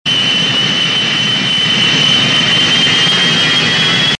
• Plane engine screaming.wav
[Plane-engine-screaming-Sound-Effect]_N6H.wav